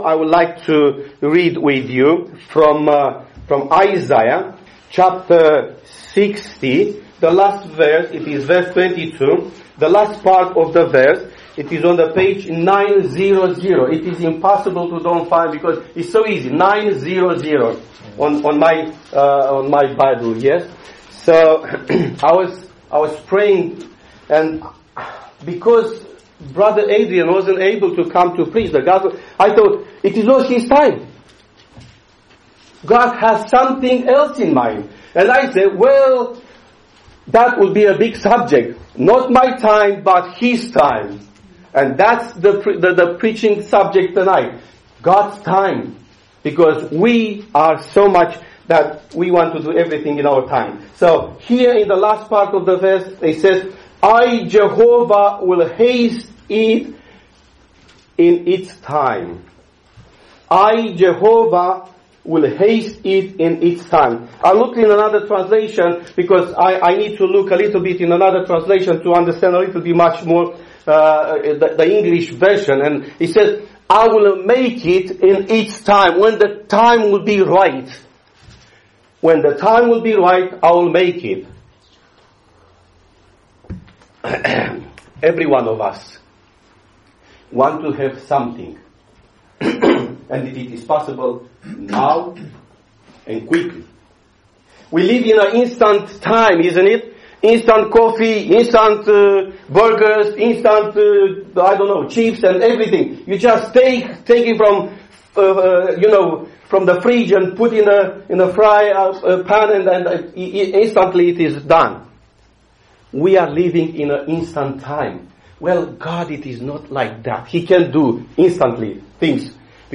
God's timing is always perfect. In this preaching, you will what the scriptures say about God timing.